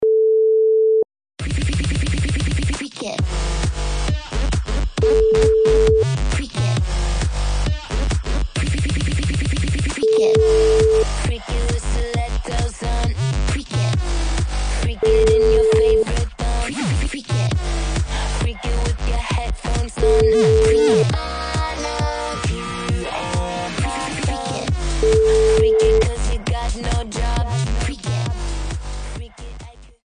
Easy Listening Dance